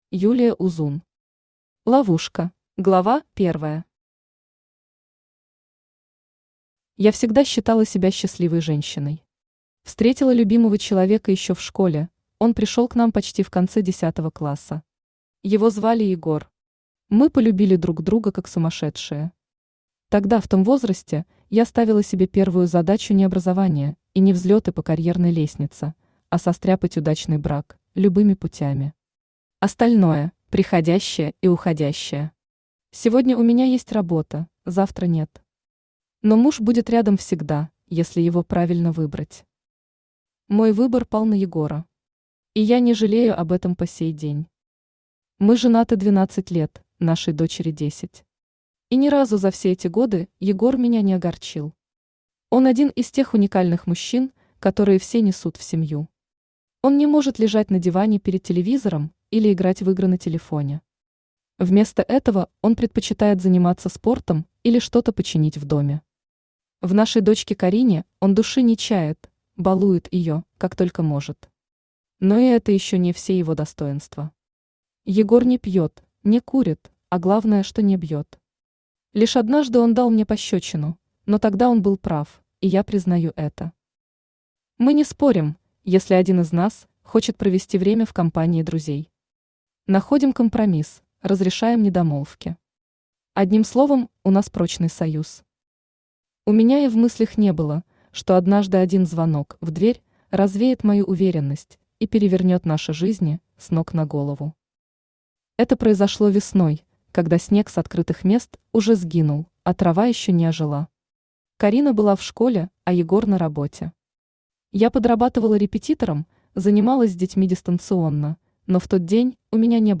Aудиокнига Ловушка Автор Юлия Узун Читает аудиокнигу Авточтец ЛитРес.